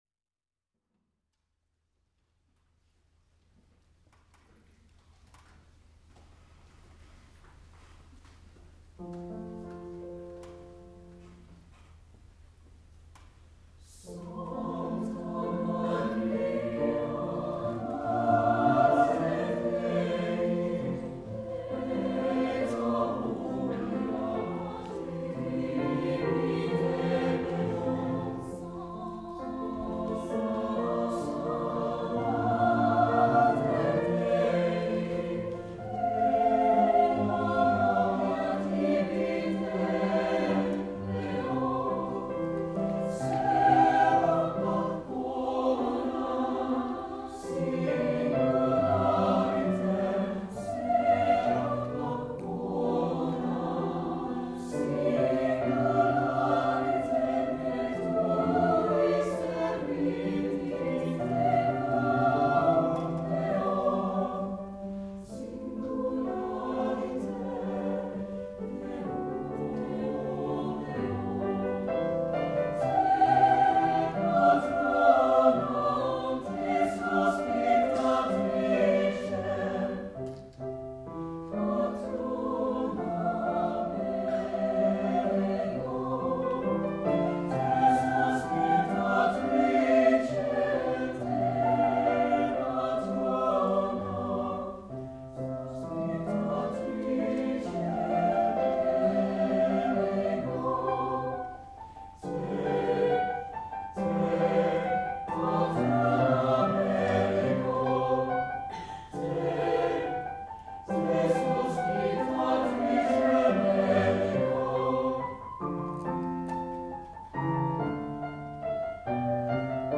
CSUB+Chamber+Choir+Festival+2017+-+Centennial+HS.m4a